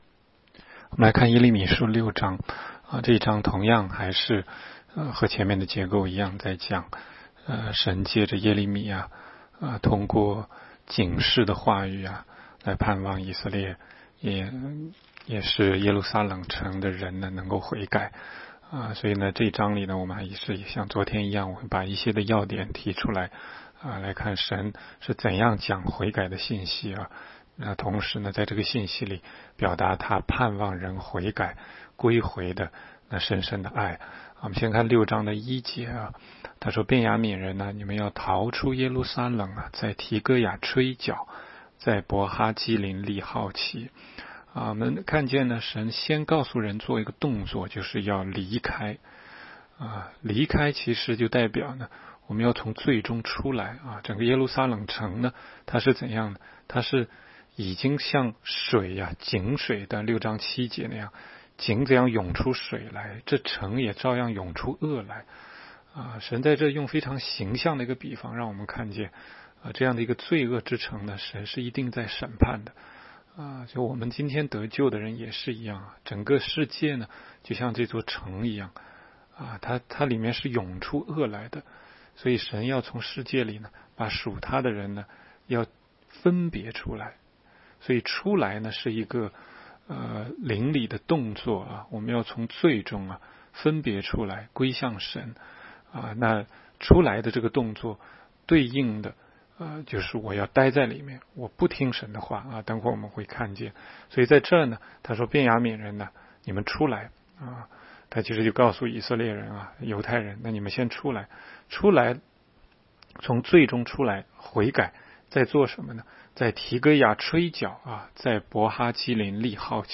16街讲道录音 - 每日读经 -《耶利米书》6章
每日读经